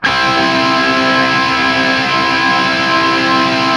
TRIAD C  L-L.wav